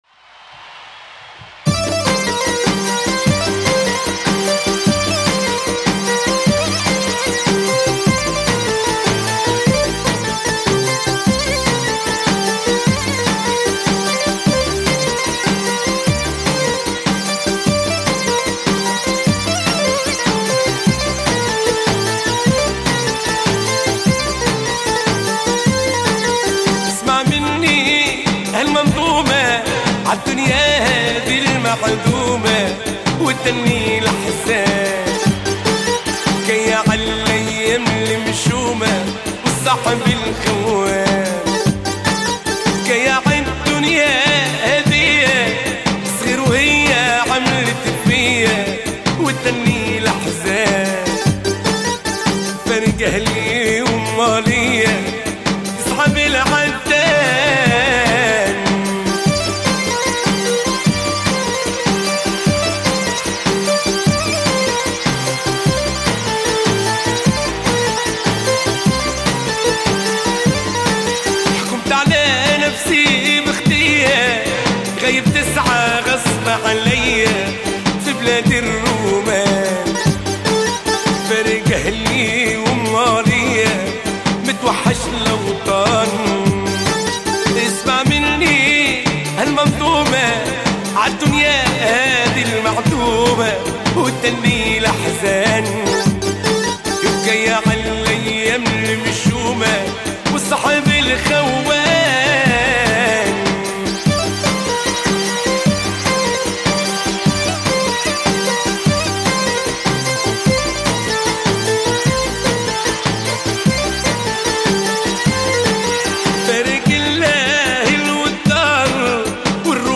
Chanteurs
Bienvenue au site des amateurs de Mezoued Tunisien